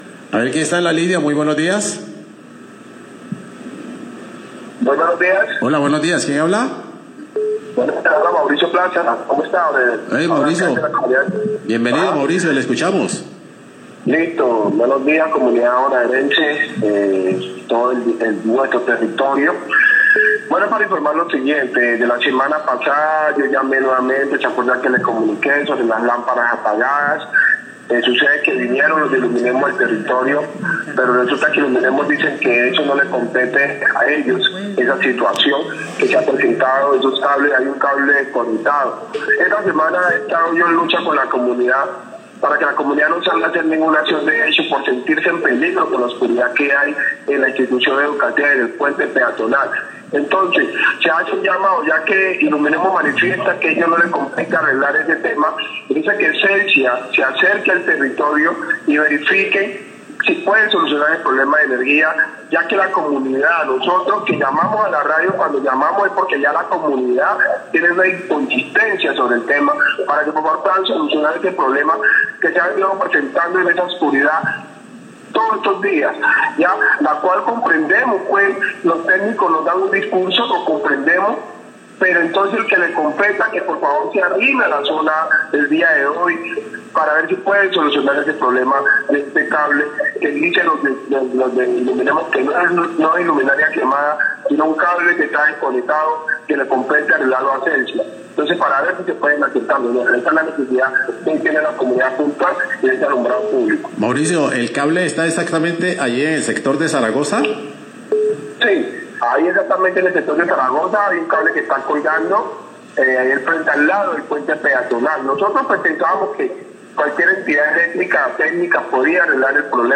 Oyente hace llamado a Iluminemos y Celsia por falta de alumbrado público en Zaragoza